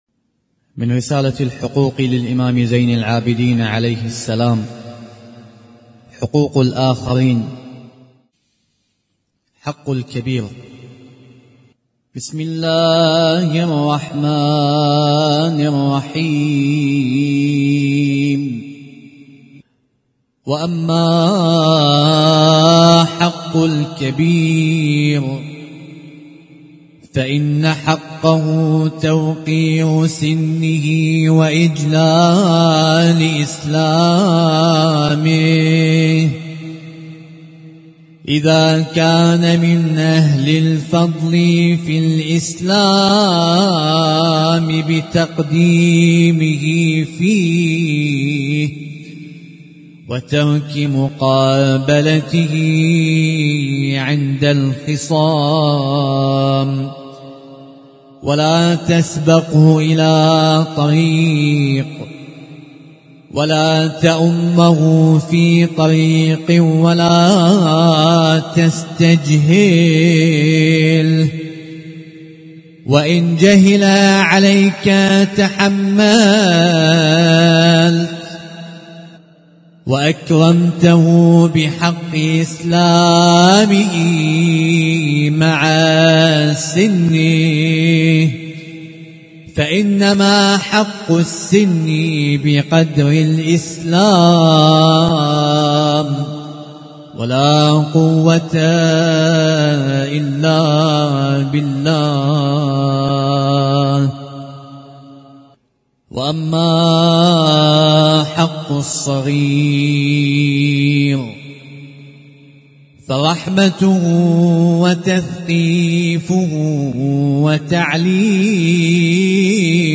القارئ